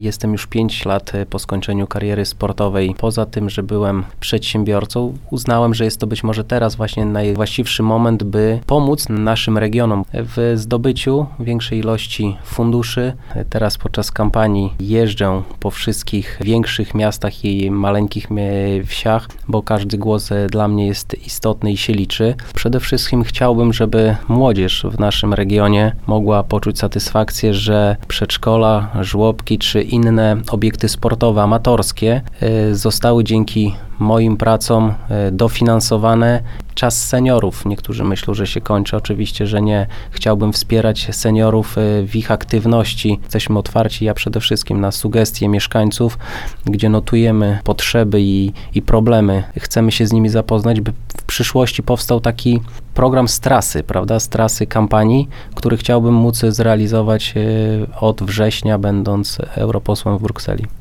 Tomasz Frankowski oficjalnie rozpoczął kampanię wyborczą do Parlamentu Europejskiego. Dziś (02.05) w Radiu 5 opowiadał o swoich planach.